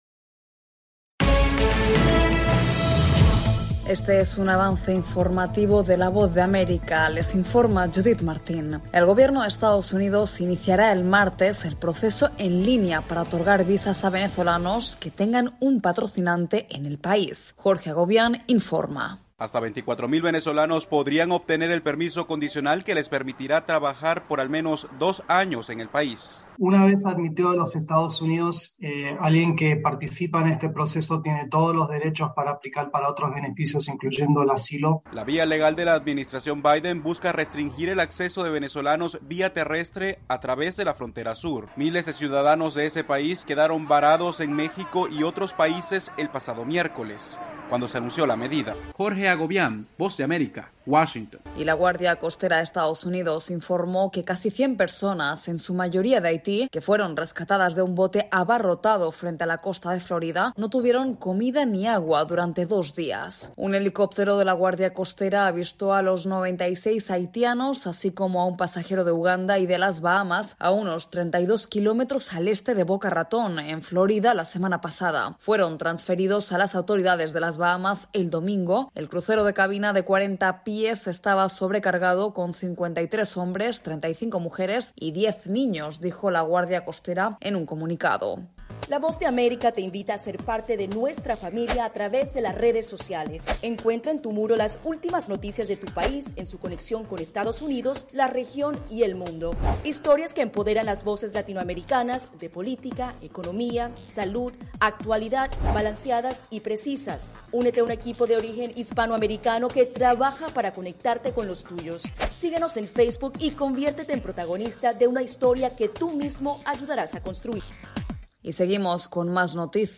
Este es un avance informativo de la Voz de América...